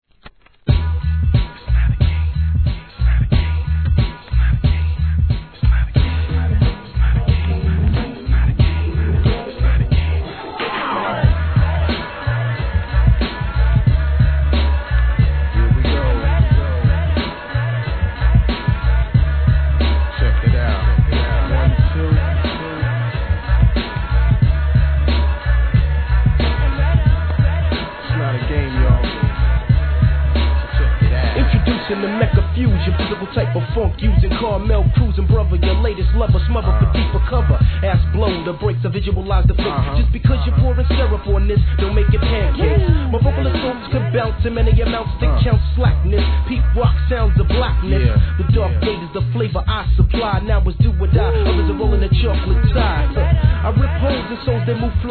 HIP HOP/R&B
極上のリラクゼーションを味わえる傑作中の傑作!!